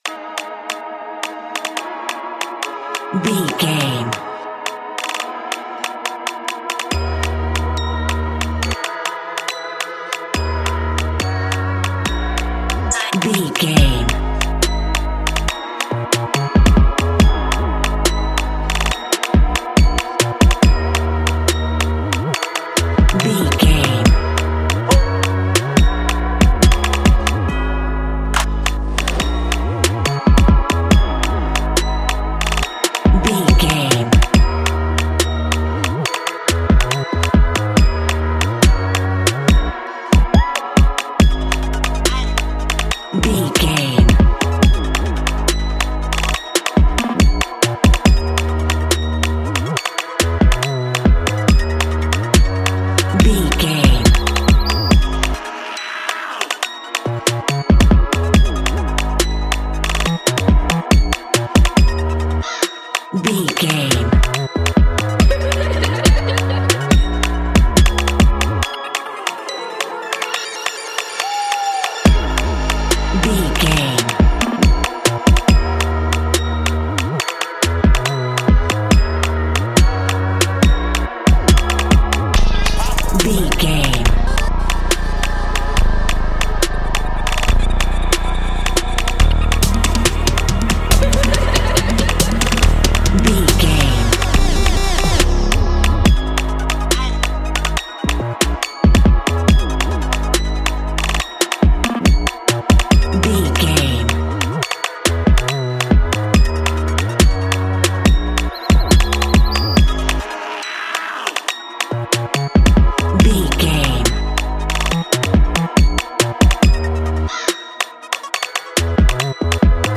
Ionian/Major
Fast